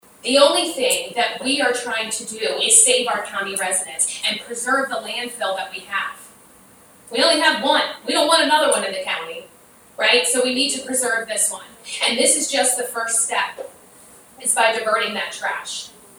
Wicomico County Executive Julie Giordano at a news conference on Monday talked about the goal they are trying to accomplish…